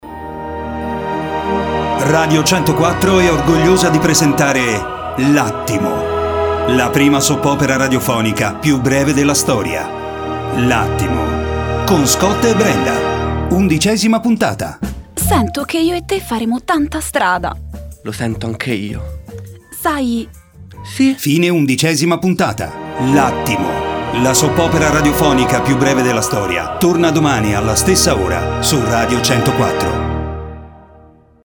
L’Attimo – la Soap opera più breve della storia. Undicesima puntata